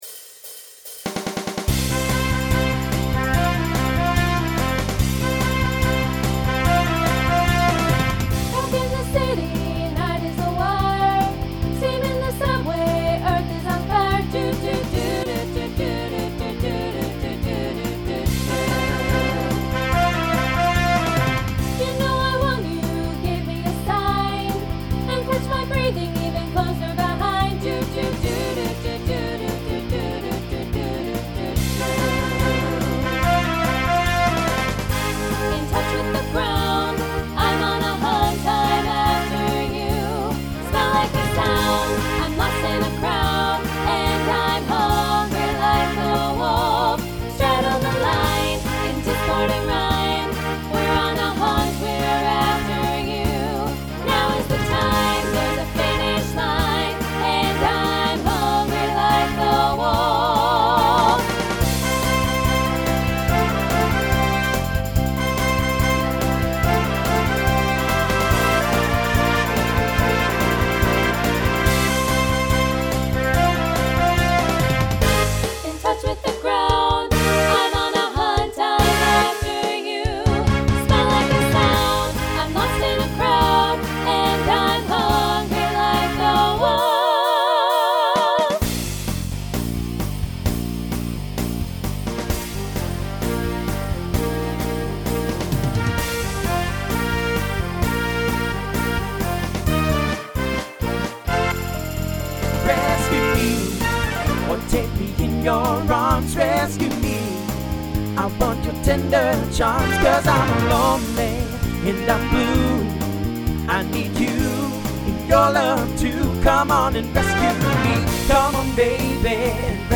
TTB/SSA
Voicing Mixed Instrumental combo Genre Rock